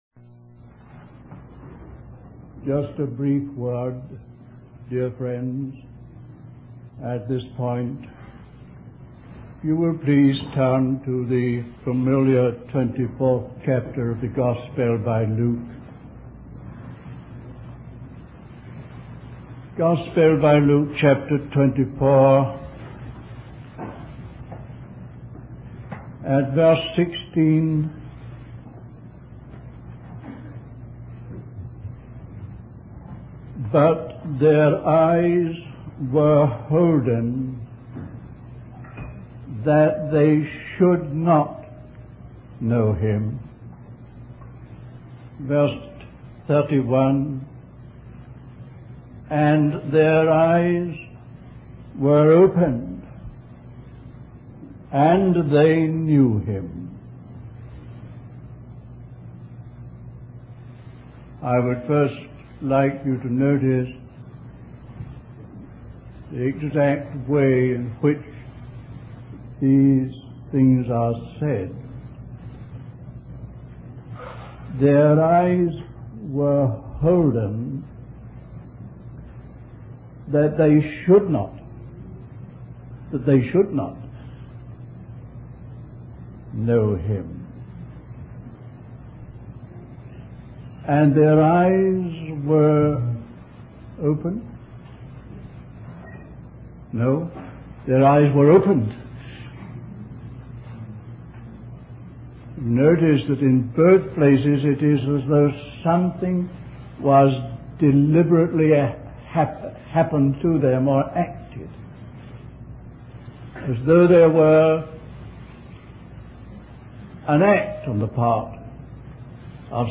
Wabanna (Atlantic States Christian Convocation)